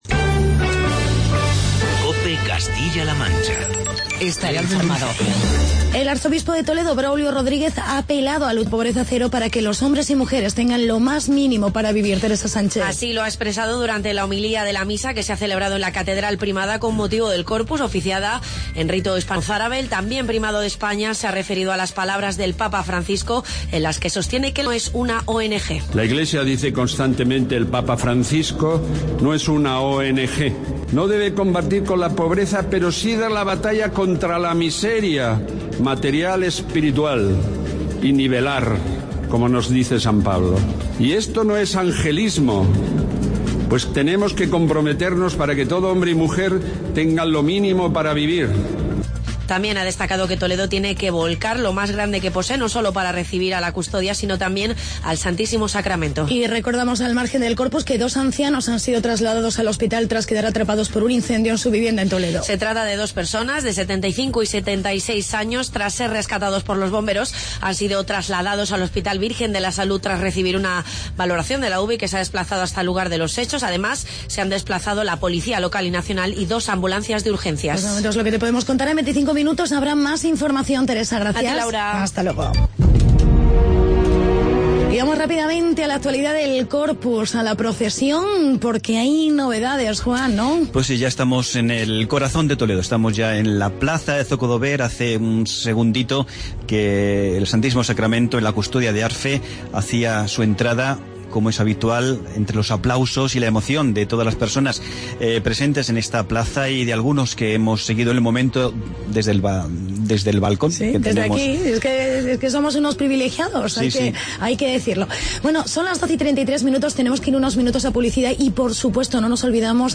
Programa Especial Corpus Christi con Reportajes sobre Albacete y Cuenca.